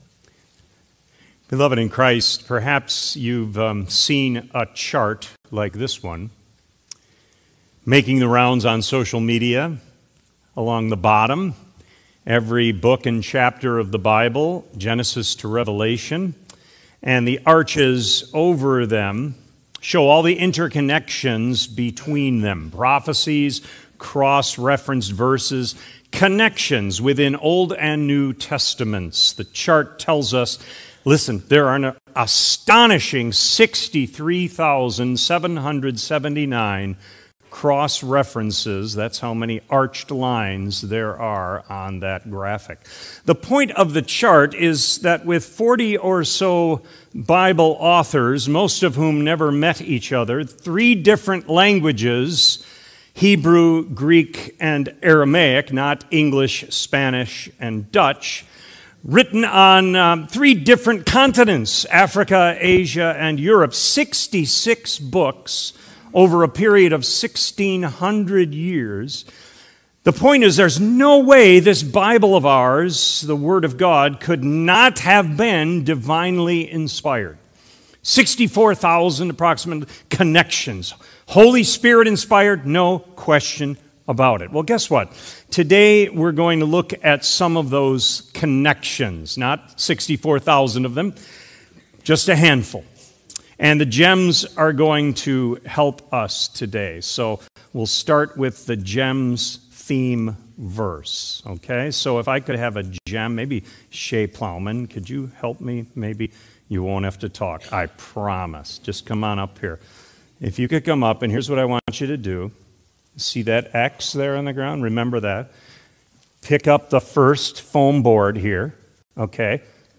Sermon Recordings | Faith Community Christian Reformed Church
“Step Out in Faith” March 22 2026 A.M. Service